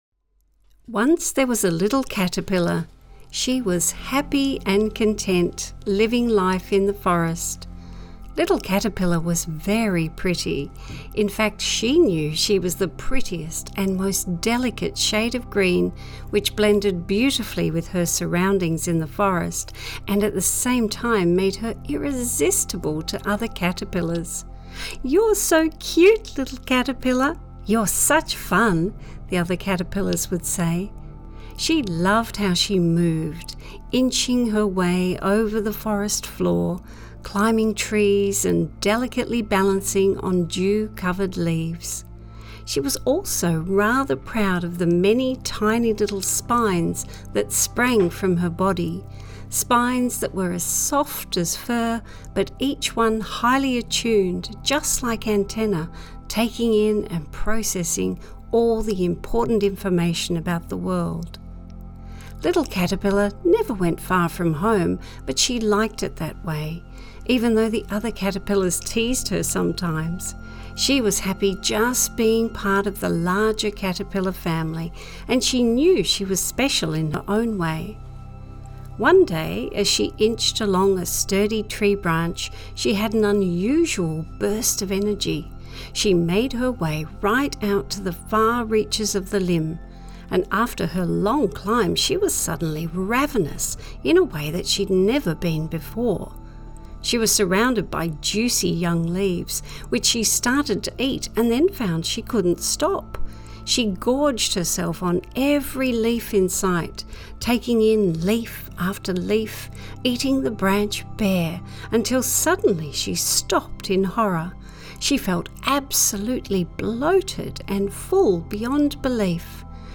Caterpillar to Butterfly - A Guided Meditation